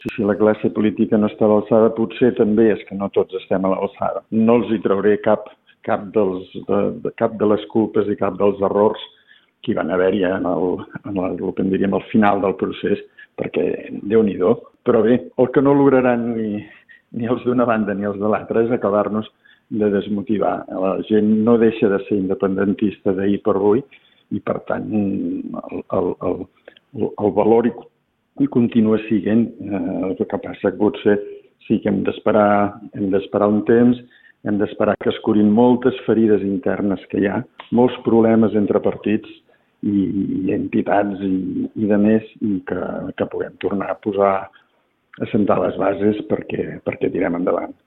en una entrevista a RCT.